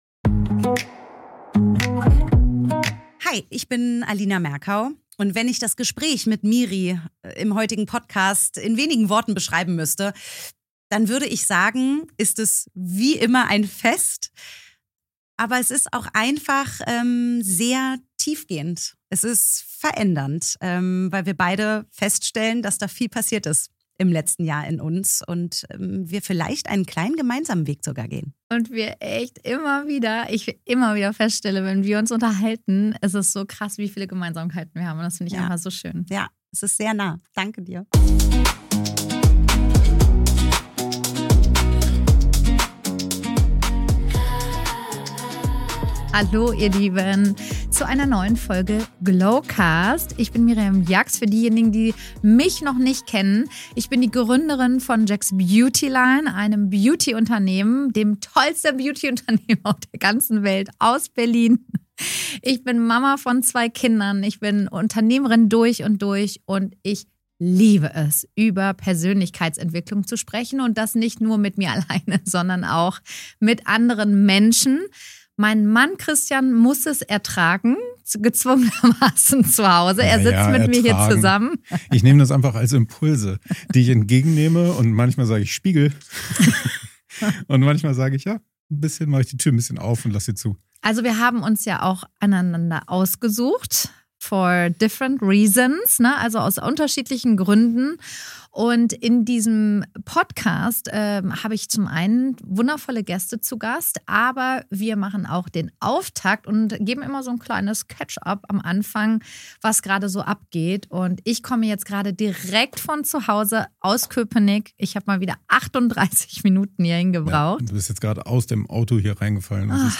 Alina Merkau im Talk über ihr mutiges Auslandsjahr auf Ibiza. Wie schafft man den Spagat zwischen Sat.1 Frühstücksfernsehen, Business und Familie?